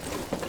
Gear Rustle Redone
tac_gear_11.ogg